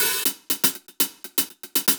Index of /musicradar/ultimate-hihat-samples/120bpm
UHH_AcoustiHatA_120-03.wav